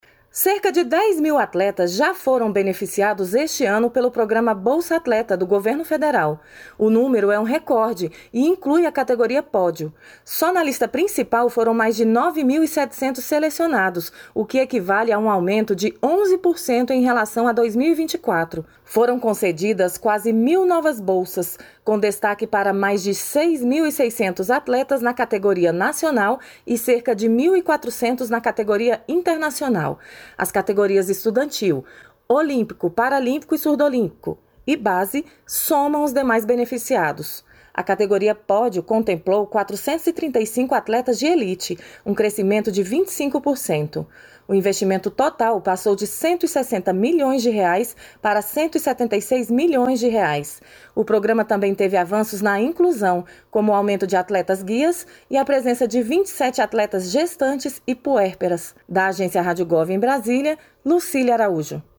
PRONUNCIAMENTO DO MINISTRO DA EDUCAÇÃO, JOSÉ MENDONÇA BEZERRA FILHO